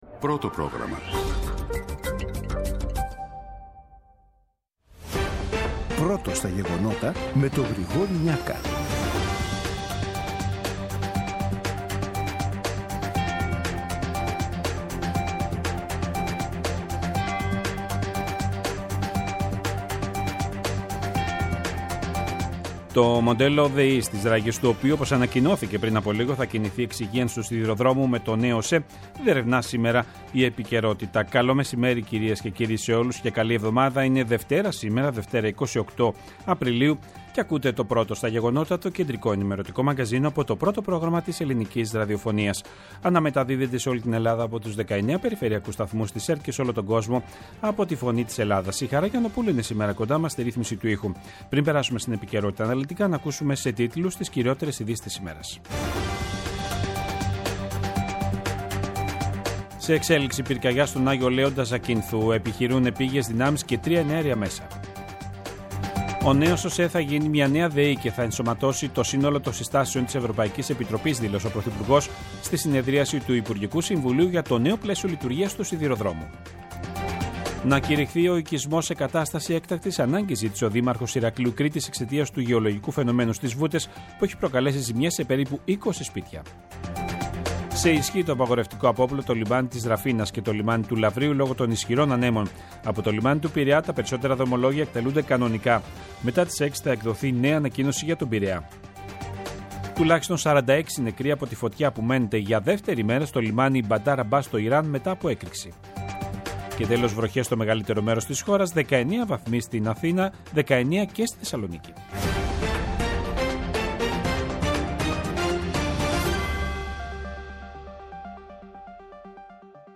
Το αναλυτικό ενημερωτικό μαγκαζίνο του Α΄ Προγράμματος, από Δευτέρα έως Παρασκευή στις 14:00. Με το μεγαλύτερο δίκτυο ανταποκριτών σε όλη τη χώρα, αναλυτικά ρεπορτάζ και συνεντεύξεις επικαιρότητας. Ψύχραιμη ενημέρωση, έγκυρη και έγκαιρη.